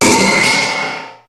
Cri de Méga-Scarabrute dans Pokémon HOME.
Cri_0127_Méga_HOME.ogg